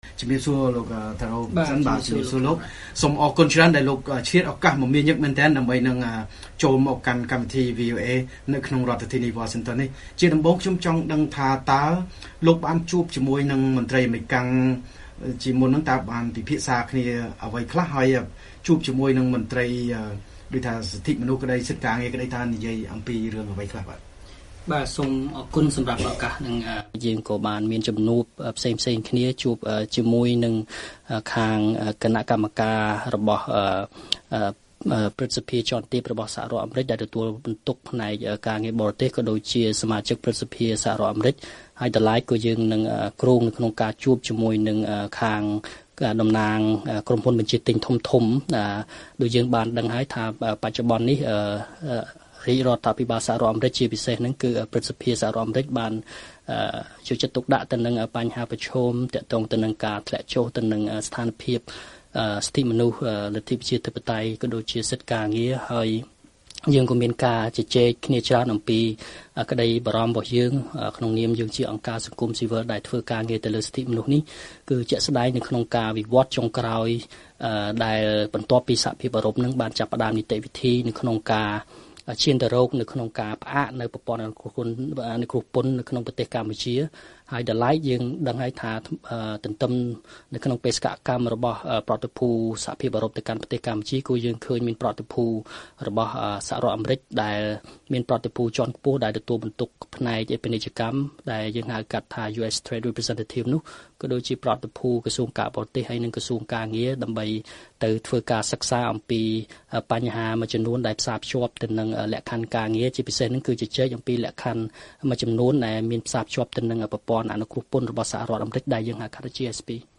បទសម្ភាសន៍ VOA៖ អ្នកតស៊ូមតិបង្ហាញពីស្ថានភាពសិទ្ធិមនុស្សនិងសិទ្ធិការងារនៅកម្ពុជាដល់មន្ត្រីអាមេរិក